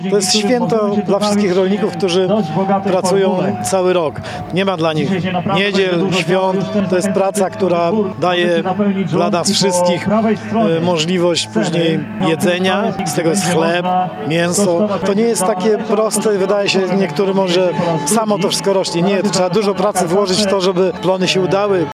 – To dzień, w którym dziękujemy rolnikom za ich całoroczną, ciężką pracę – podkreśla starosta powiatu ełckiego, Marek Chojnowski.